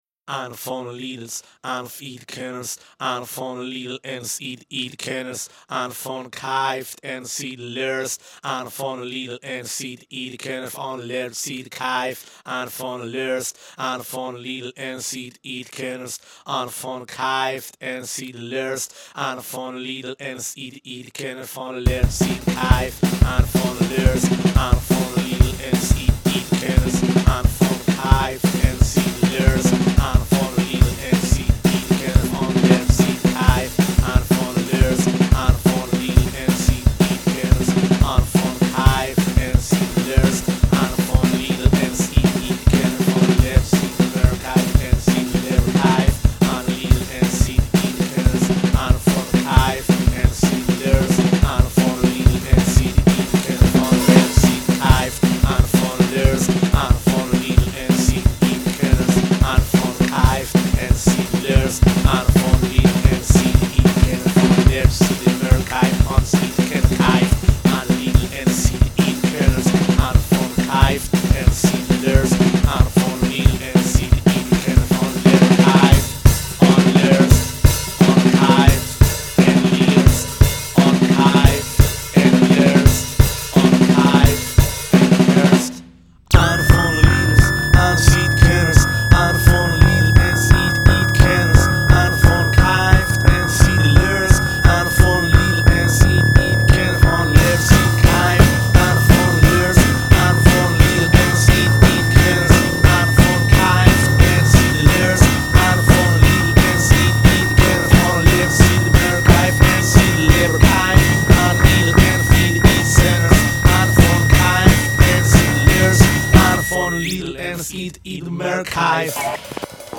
batería, dobro y voz
guitarra
hardware hacking
bajo